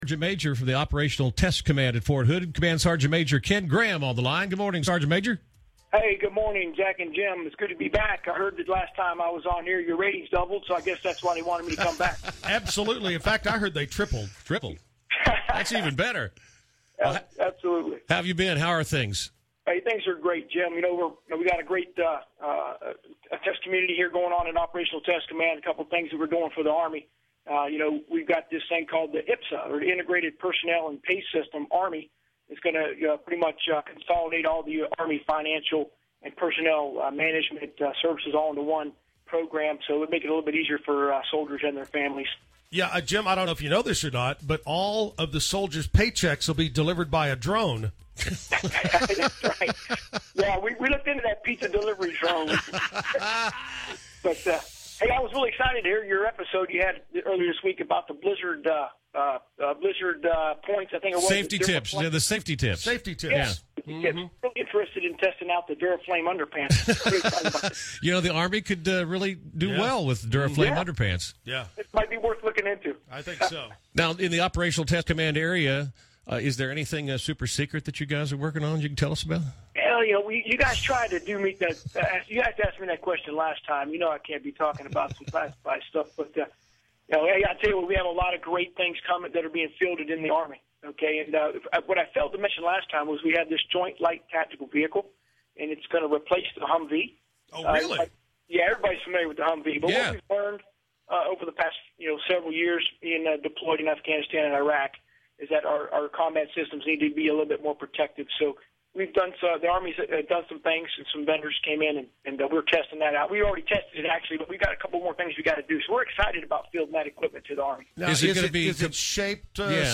Radio interview with Command Sgt. Maj.